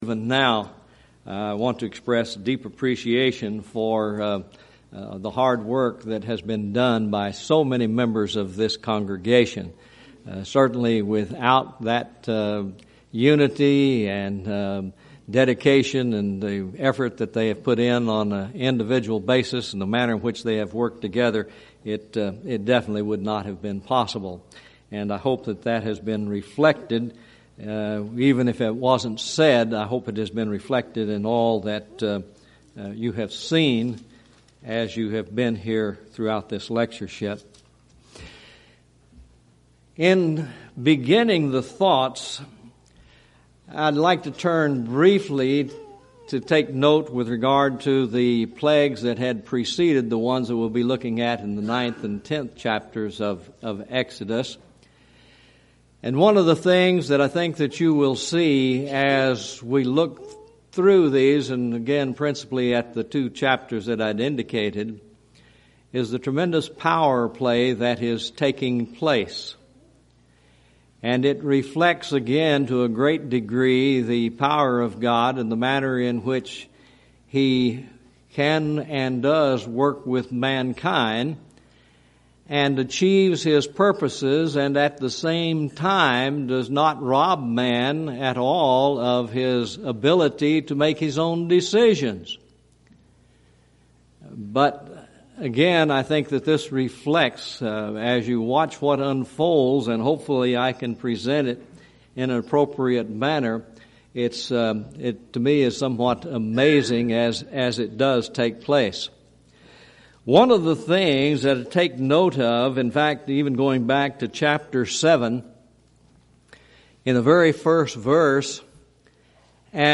Event: 2nd Annual Schertz Lectures
lecture